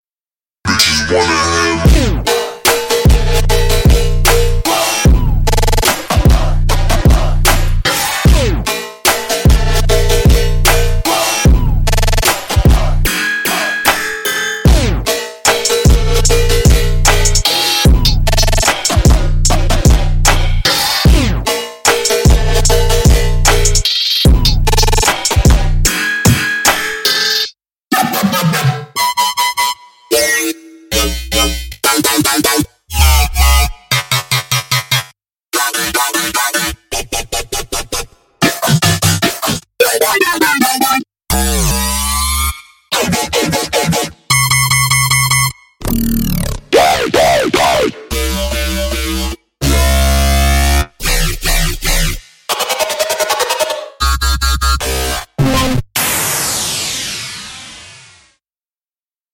您将收到70多种血清预设，包括低音，音调，效果，弹奏等。